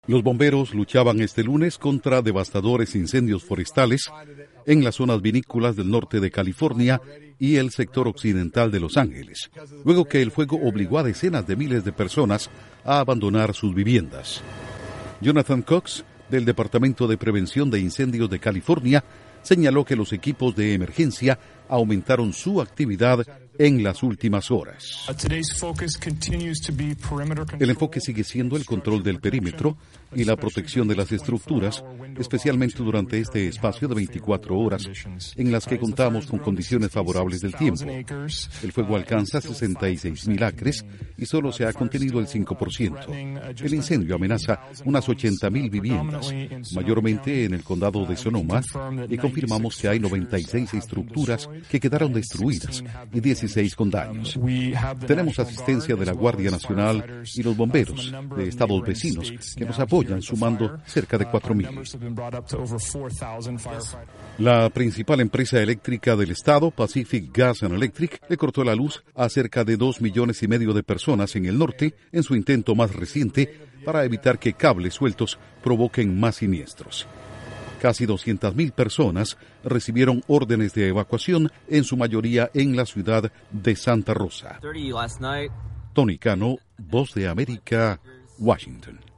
Duración: 1:29 Con declaraciones funcionarios de California